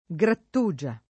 vai all'elenco alfabetico delle voci ingrandisci il carattere 100% rimpicciolisci il carattere stampa invia tramite posta elettronica codividi su Facebook grattugia [ g ratt 2J a ] s. f.; pl. -gie o -ge — cfr. grattugiare